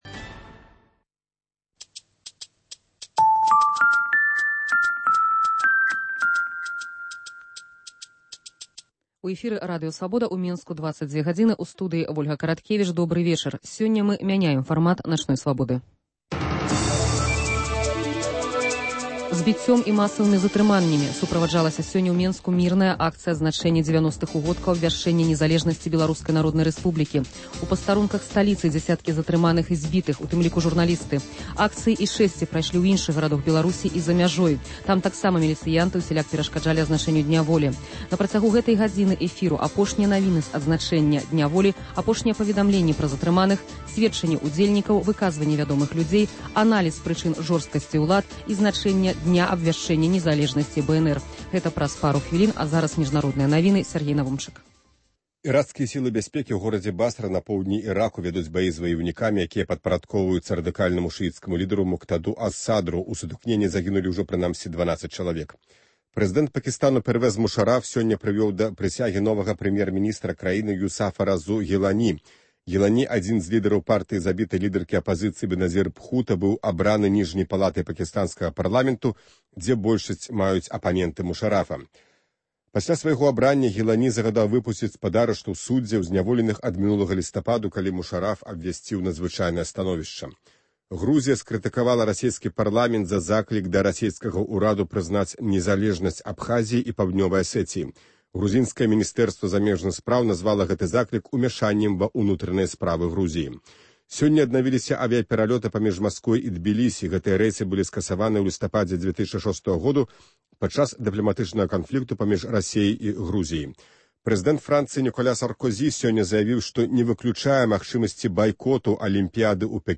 Апошнія навіны, зьвязаныя зь сьвяткаваньнем Дня Волі, анталёгія падзеяў у Менску і рэгіёнах, выказваньні вядомых людзей пра значнасьць абвяшчэньня незалежнасьці БНР. Вы пачуеце пажаданьні з нагоды сьвята Дня Волі, у тым ліку і тых грамадзянаў, якія пакуль яго не сьвяткуюць. Актуальныя званкі нашых слухачоў.